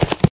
PixelPerfectionCE/assets/minecraft/sounds/mob/horse/gallop2.ogg at mc116
gallop2.ogg